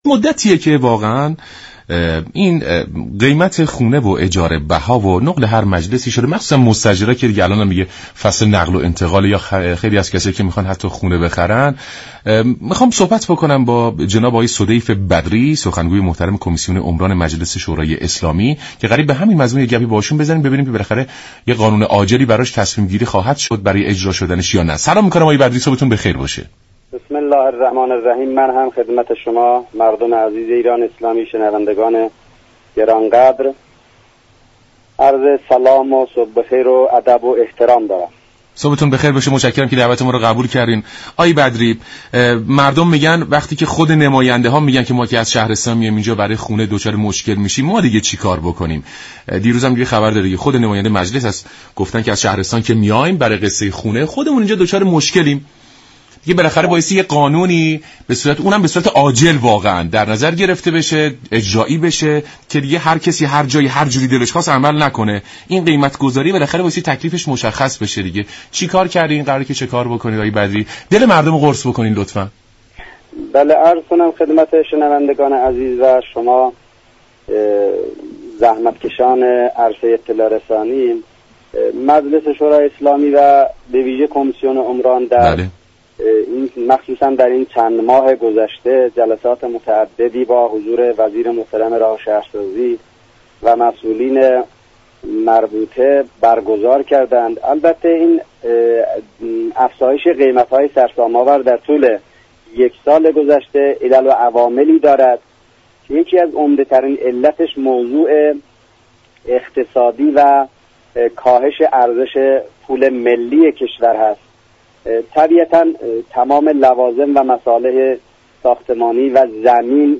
سخنگوی كمیسیون عمران مجلس در گفت و گو با رادیو ایران گفت: لوازم و مصالح ساختمانی برای حفظ ارزش پول صاحبان زمین و ملك طی ماه های اخیر با افزایش چشمگیری روبرو بوده است